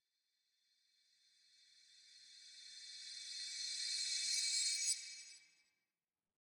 Minecraft Version Minecraft Version latest Latest Release | Latest Snapshot latest / assets / minecraft / sounds / ambient / nether / warped_forest / enish3.ogg Compare With Compare With Latest Release | Latest Snapshot